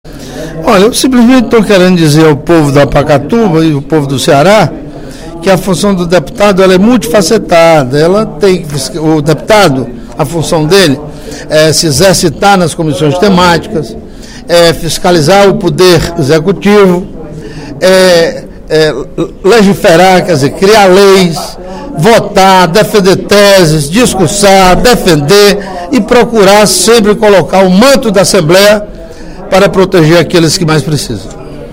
O deputado Carlomano Marques (PMDB) informou sobre o relatório, produzido pelo Tribunal de Contas dos Municípios (TCM), que faz um diagnóstico da administração municipal de Pacatuba. De acordo com o parlamentar, em pronunciamento no primeiro expediente da sessão plenária desta quinta-feira (25/06), o documento aborda denúncias feitas pelos vereadores de oposição do município.